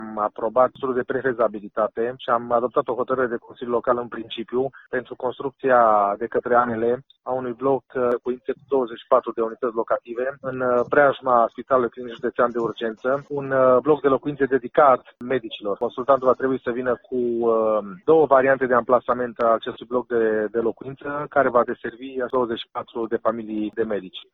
Președintele de ședință, consilierul local Sergiu Papuc: